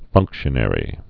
(fŭngkshə-nĕrē)